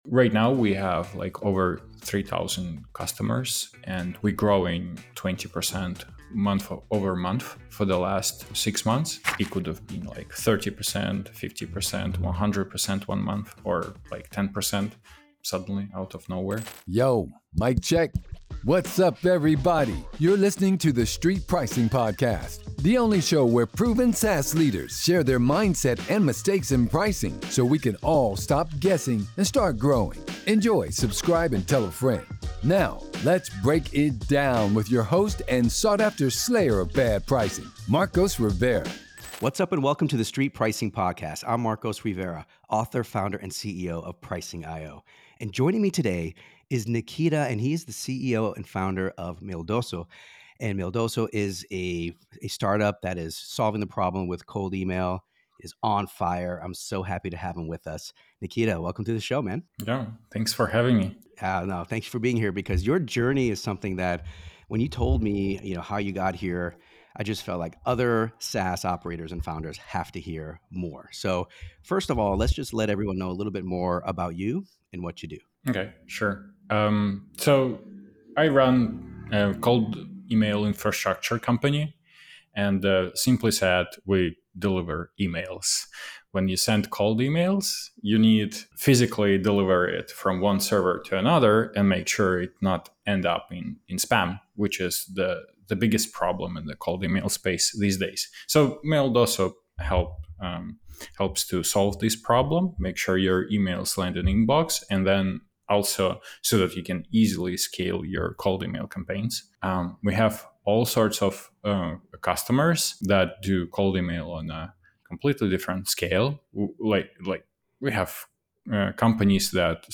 In this episode of the Street Pricing Podcast